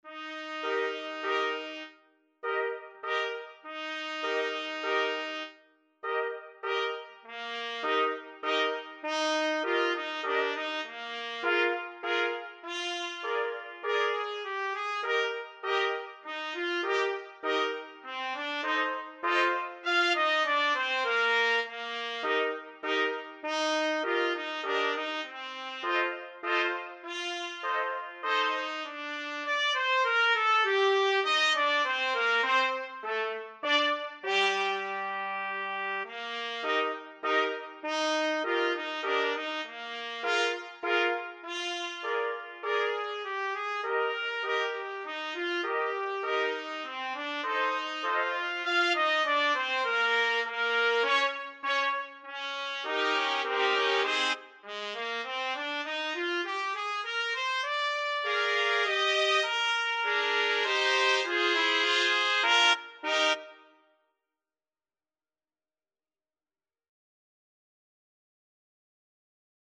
Free Sheet music for Trumpet Quartet
Trumpet 1Trumpet 2Trumpet 3Trumpet 4
Slowly = c.100
3/4 (View more 3/4 Music)